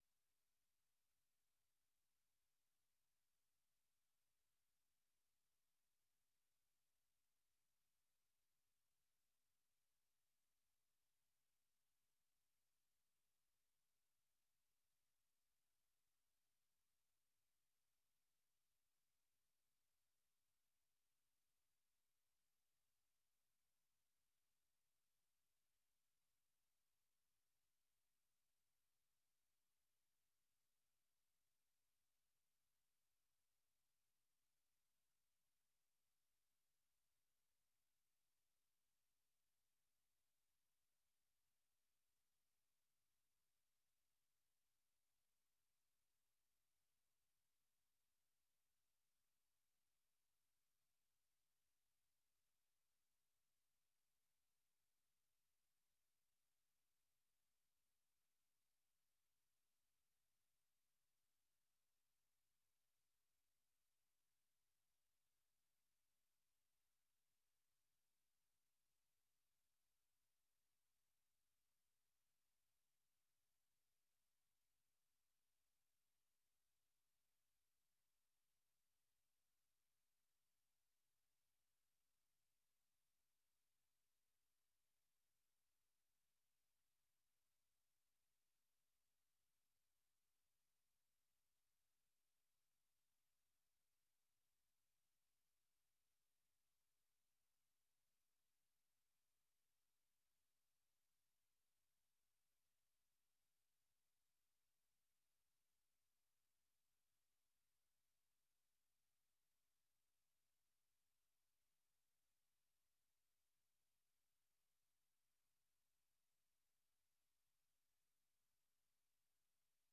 Nûçeyên 1’ê paşnîvro